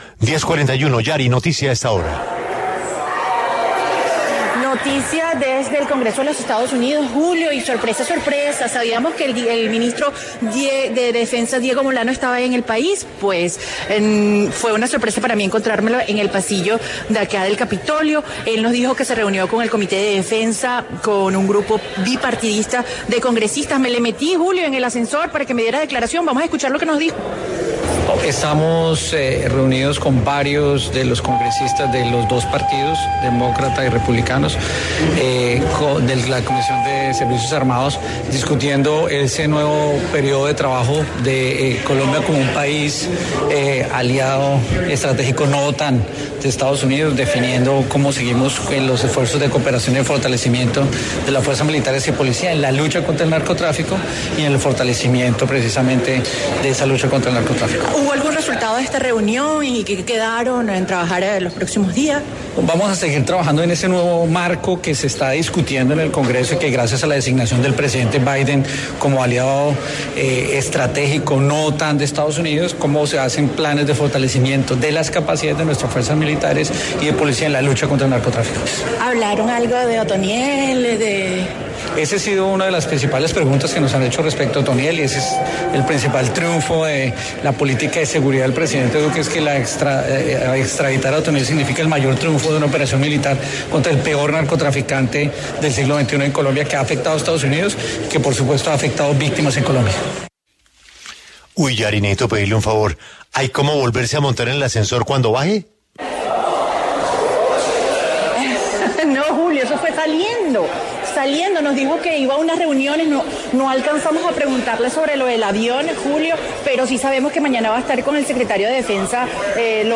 Diego Molano, ministro de Defensa, habló en La W sobre la reunión que tuvo con el Comité de Defensa y varios congresistas de Estados Unidos.